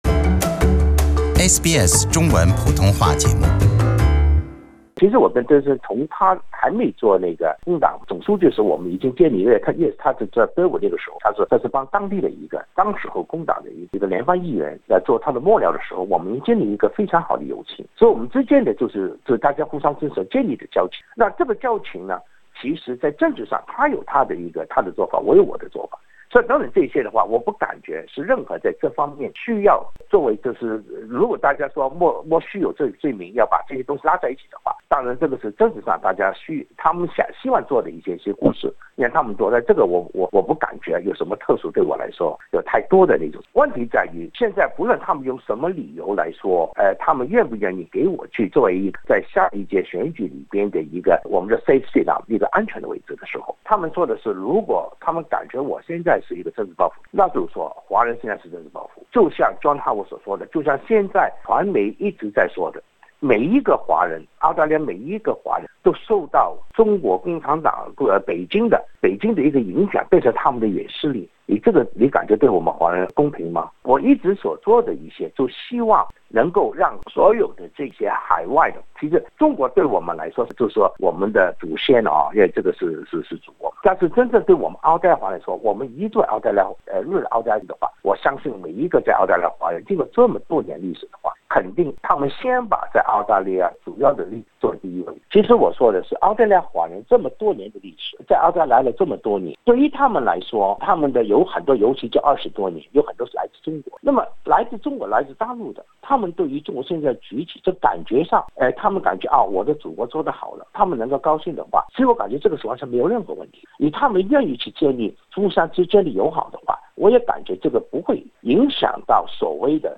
备受争议的新南威尔士州上议院华裔工党议员王国忠接受本台采访，澄清自己已经不是和统会的名誉顾问，并认为在外国游说团体注册问题上，该机构将成为澳大利亚政府的目标。